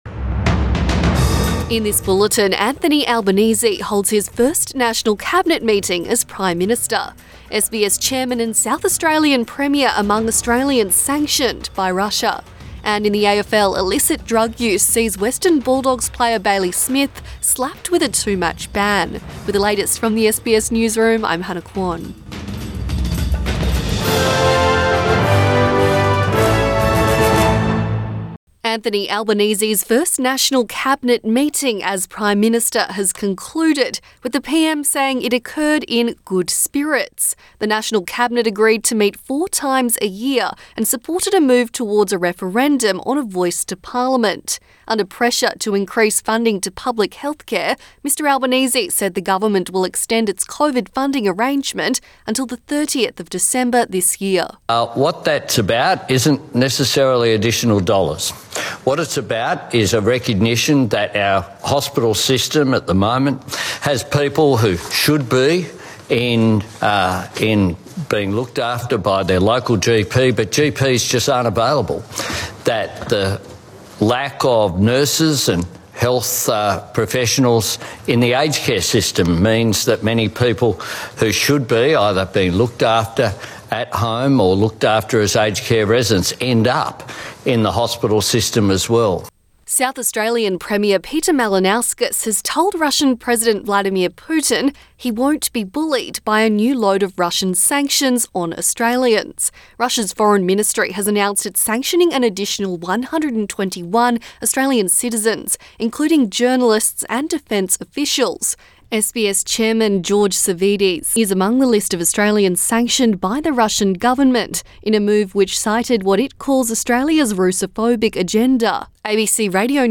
Midday bulletin 17 June 2022